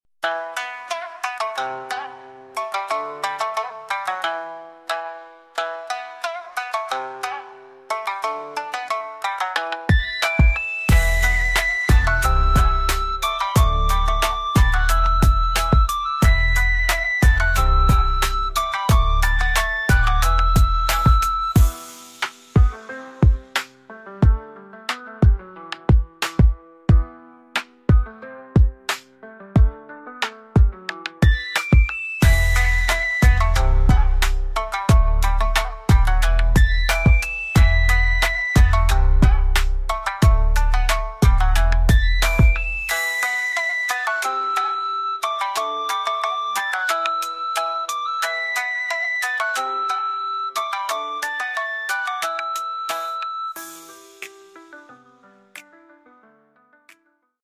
Shamisen